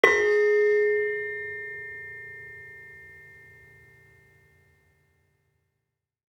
Saron-3-G#3-f.wav